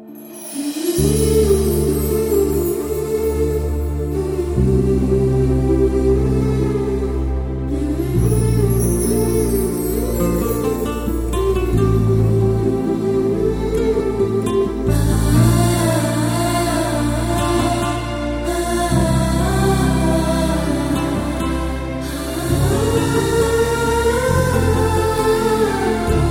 released in 2006 with a gentle melody and emotional lyrics.